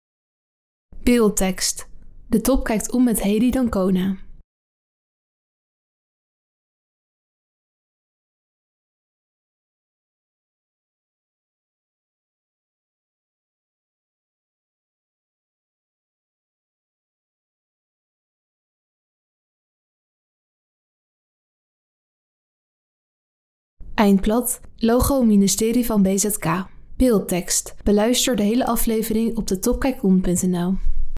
Je kunt het interview met Hedy d'Ancona op drie manieren volgen: